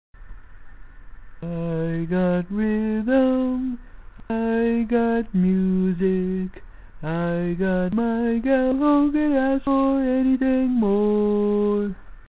Key written in: D♭ Major
Each recording below is single part only.